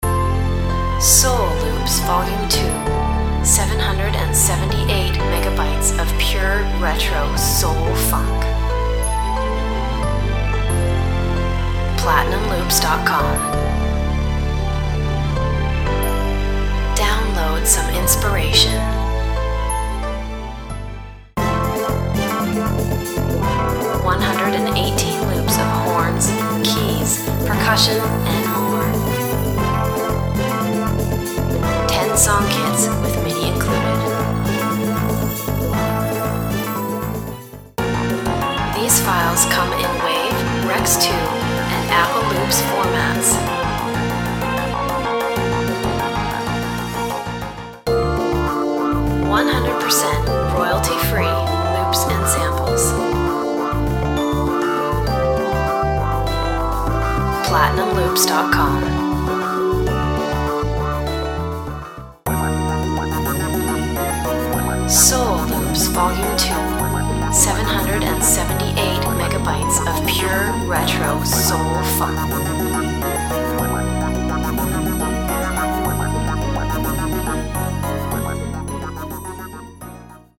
Tempos range from 88 to 115 bpm.